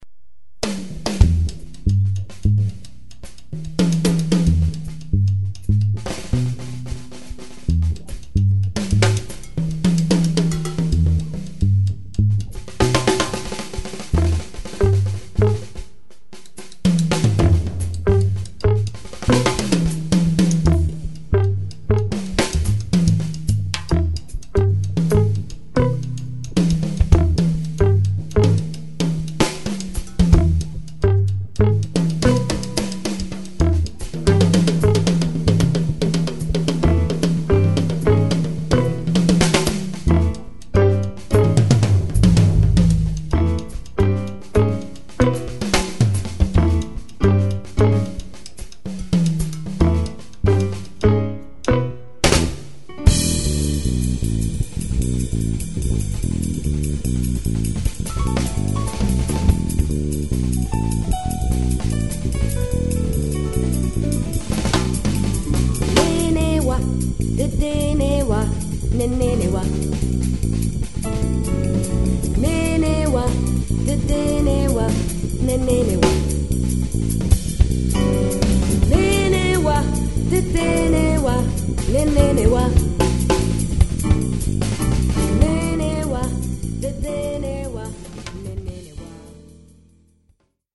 batteria, percussioni, voci
piano, tastiere, voci
chitarre, voci
basso, percussioni, charango, voci